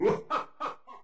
Dr. Crygor laughing in WarioWare, Inc.: Mega Party Game$!.
Source Played in "Sound Test" menu; recorded using an emulator Image help • Image use policy • Media file guidelines Licensing [ edit ] Fair use sound clip This is a sound clip from a copyrighted work.
Laughing_Dr_Crygor_WWMPG.oga.mp3